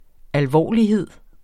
Udtale [ alˈvɒˀliˌheðˀ ]